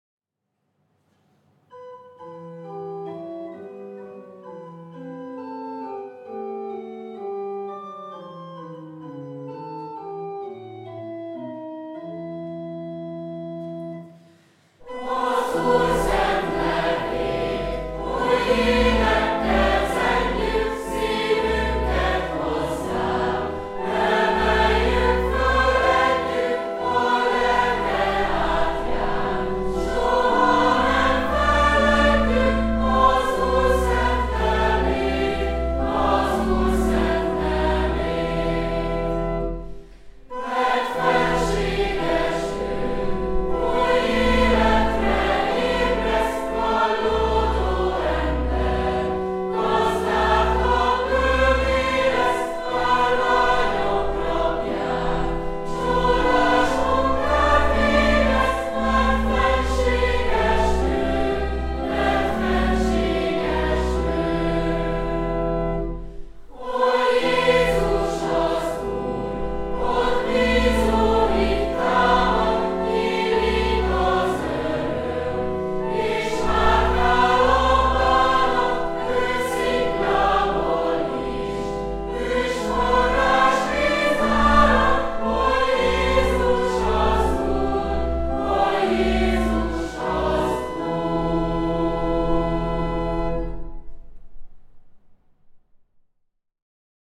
Dallam: Brazíliai dallam.
A sodró lendületű, tüzes énekmód mellett csak többszöri éneklés után nyílik meg teljesen a vers értelme, amelynek központi gondolata talán ez a mondat: „Kallódó ember gazdát kap: övé (azaz Istené) lesz.” Így válik e fülbemászó dallamú, táncos ének hitvallássá a függőségekből is megszabadítani tudó Úr erejéről.